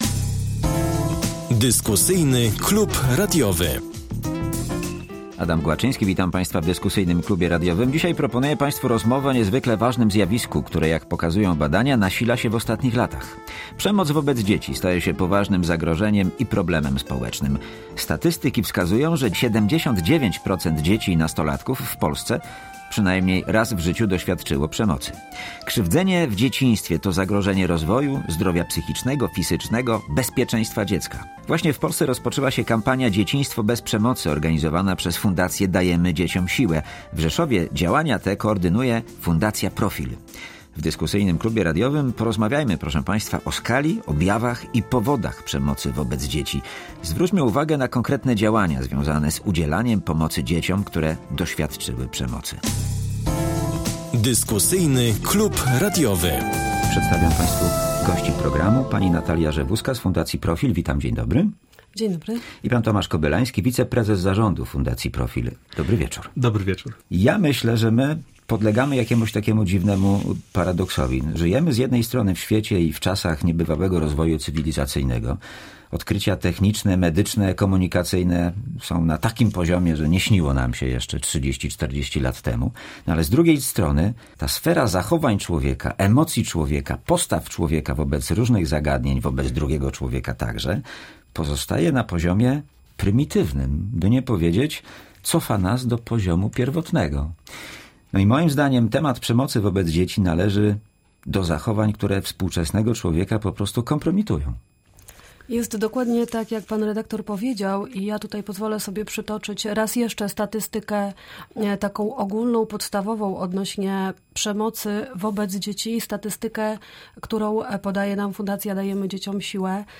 W Dyskusyjnym Klubie Radiowym rozmowa o niezwykle ważnym zjawisku, które, jak pokazują badania, nasila się w ostatnich latach. Przemoc wobec dzieci staje się poważnym zagrożeniem i problemem społecznym.